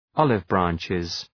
{‘ɒlıvbræntʃız}